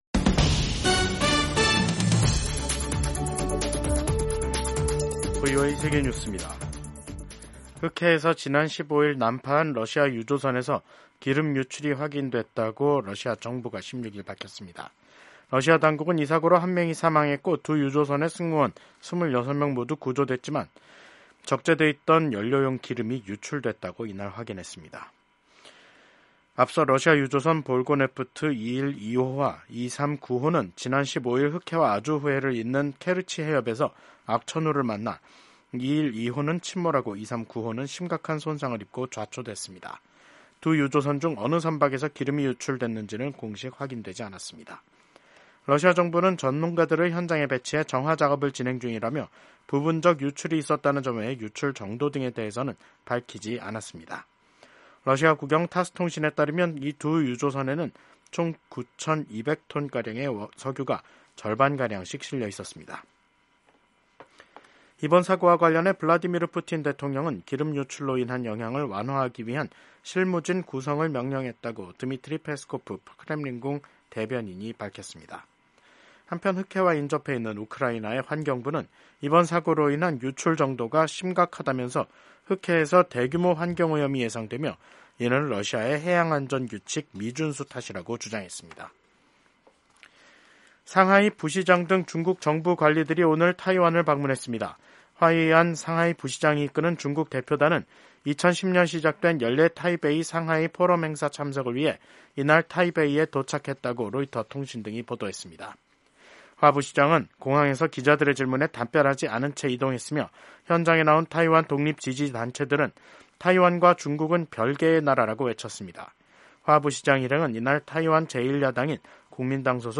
세계 뉴스와 함께 미국의 모든 것을 소개하는 '생방송 여기는 워싱턴입니다', 2024년 12월 16일 저녁 방송입니다. 이스라엘 정부가 국제법상 불법 점령 중인 골란고원에 정착촌을 더 확대하는 계획을 승인했습니다. ‘12.3 비상계엄’ 사태로 탄핵소추된 윤석열 한국 대통령에 대한 탄핵심판이 27일 시작됩니다. 유럽연합(EU)이 역내 자유로운 이동을 보장하는 솅겐조약 정식 회원국으로 불가리아와 루마니아의 가입을 최종 승인했습니다.